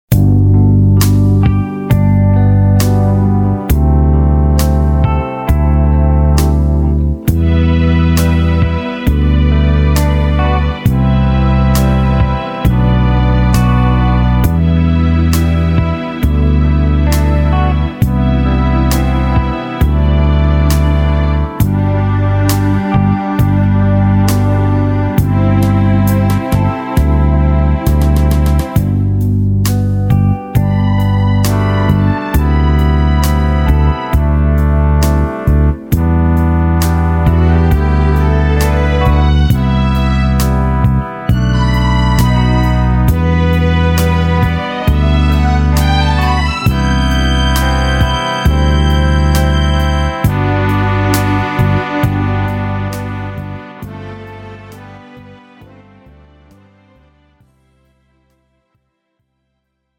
장르 pop 구분